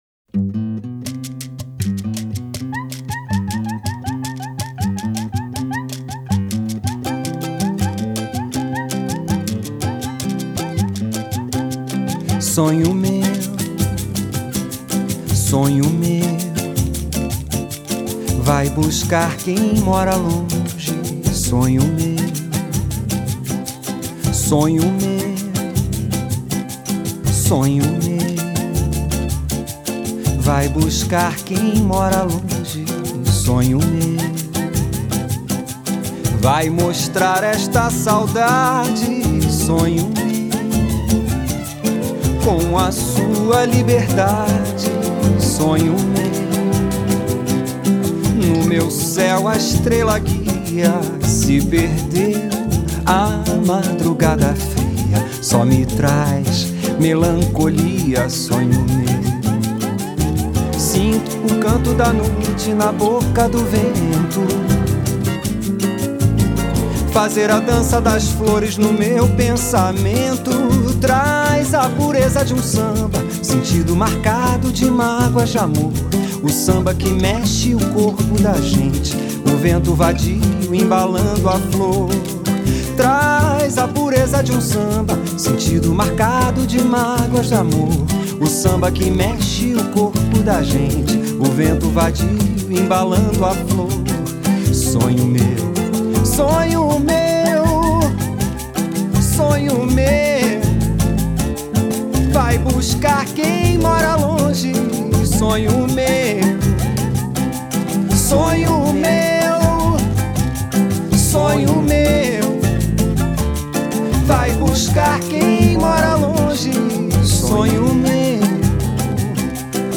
The one that makes the monkey noise.